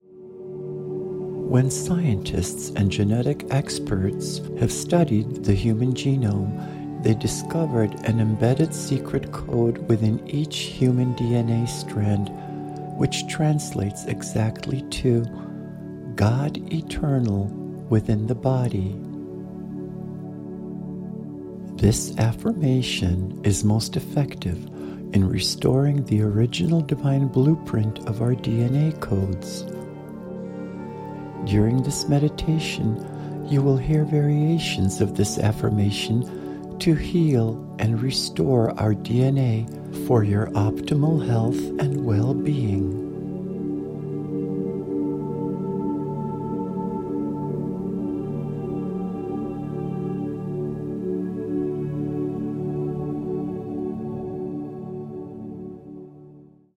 A 30 minute guided meditation
healing music and customized  affirmations